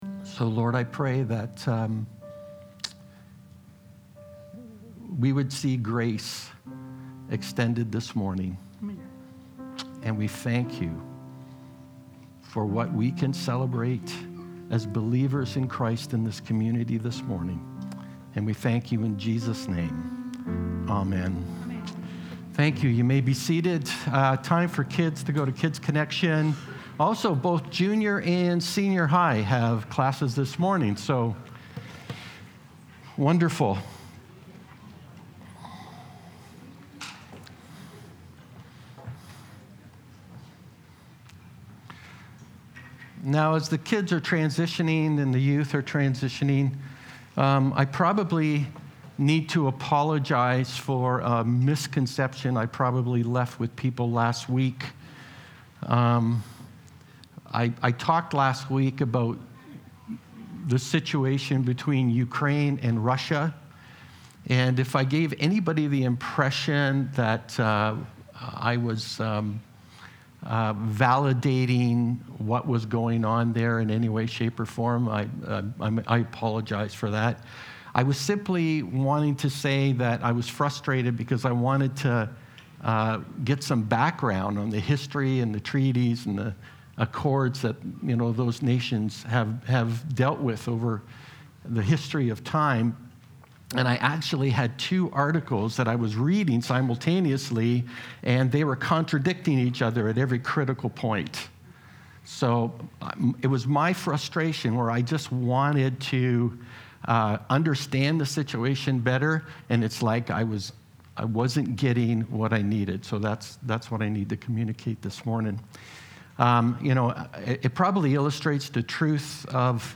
The letters of the New Testament contain a wealth of teachings and guidance for Christians. This is the second of two messages on the NT letters. 1 Peter 1:1-7 life group notes powerpoint